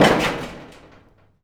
metal_sheet_impacts_01.wav